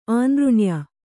♪ ānřṇya